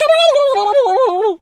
turkey_ostrich_hurt_gobble_06.wav